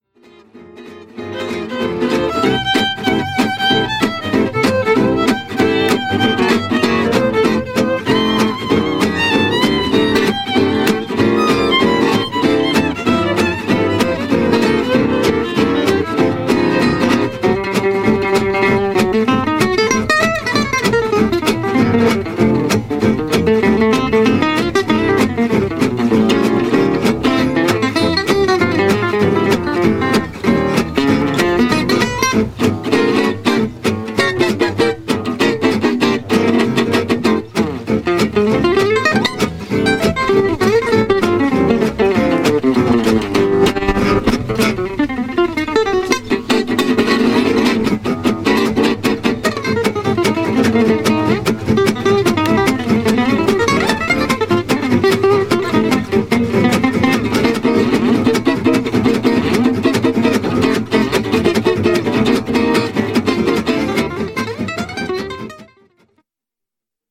guitare solo
violon
guitare rythmique
contrebasse